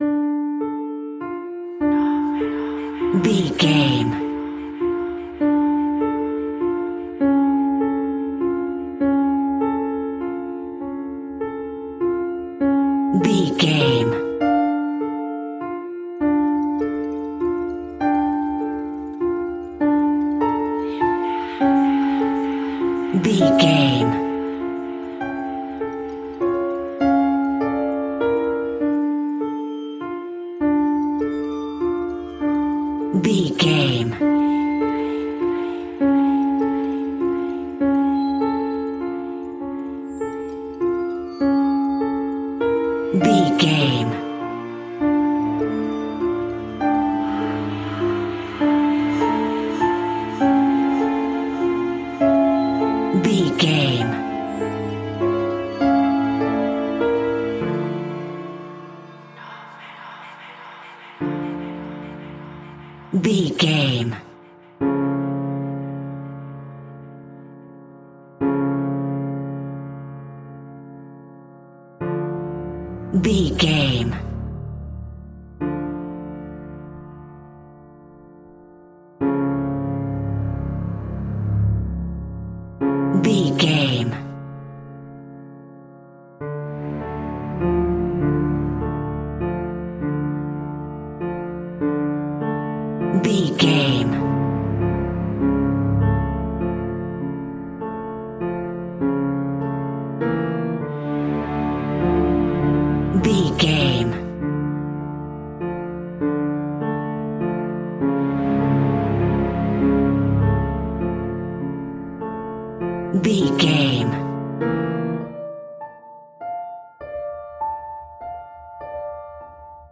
Aeolian/Minor
D
scary
tension
ominous
dark
haunting
eerie
piano
strings
cymbals
gongs
viola
french horn trumpet
taiko drums
timpani